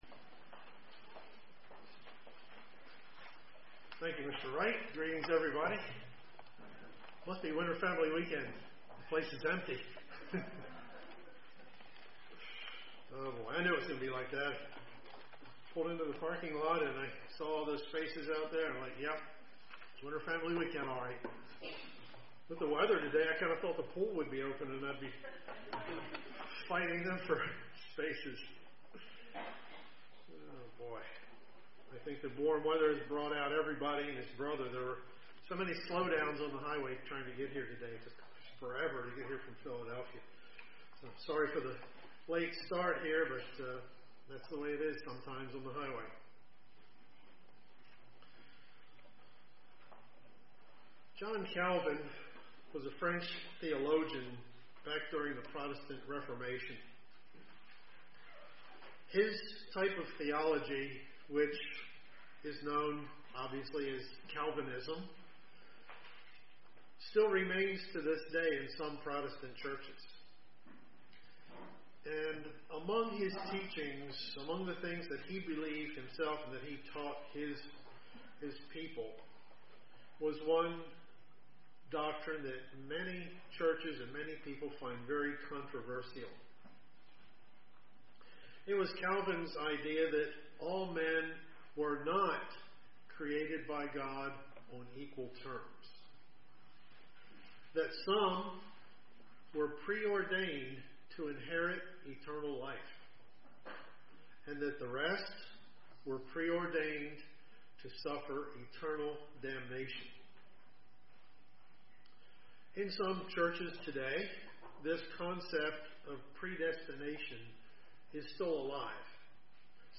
Print There are things that God does plan for us individually, and there are things he doesn't. UCG Sermon Studying the bible?